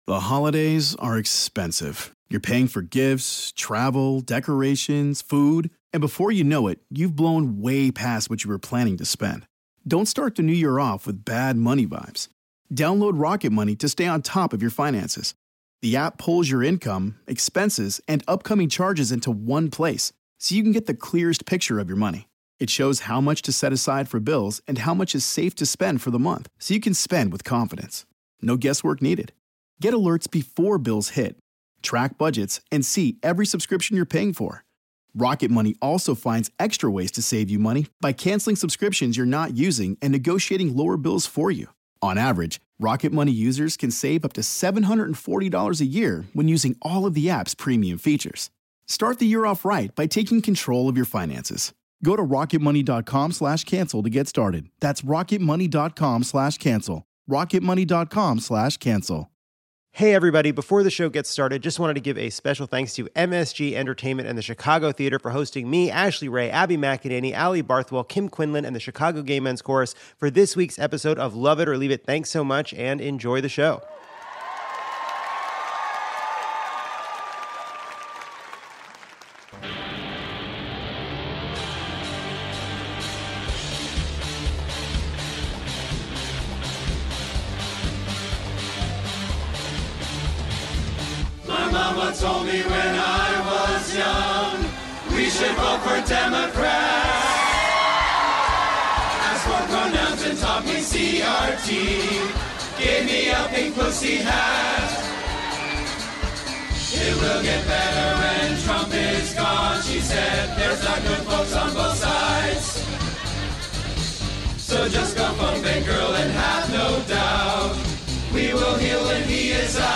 It’s bottoms up when Lovett or Leave It rolls into town for a show at the beautiful Chicago Theater. The Chicago Gay Men's Chorus reminds us we were born this way: tired and gay.